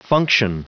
Prononciation du mot function en anglais (fichier audio)
Prononciation du mot : function